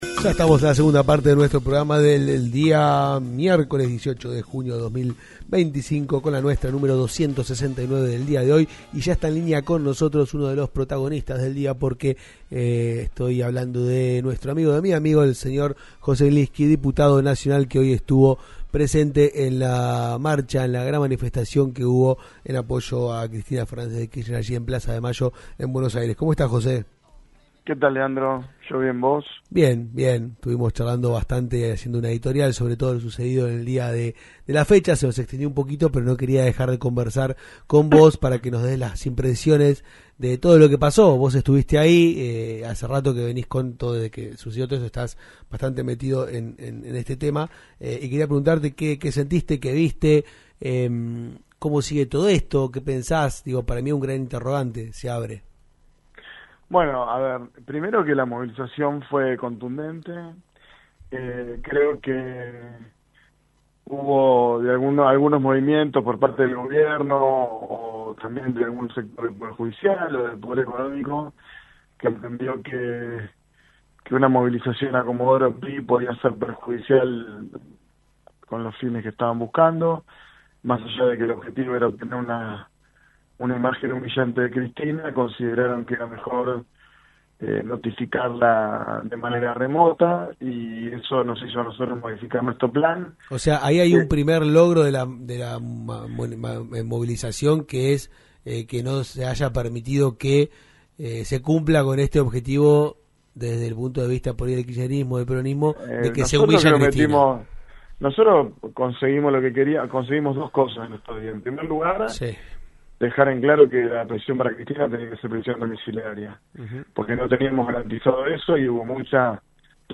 José Glinski, diputado nacional por Chubut por el bloque Unión por la Patria, dialogó en "Con La Nuestra" por LaCienPuntoUno sobre lo que fue la multitudinaria marcha en Plaza de Mayo de la Ciudad de Buenos Aires en apoyo a Cristina Fernández de Kirchner.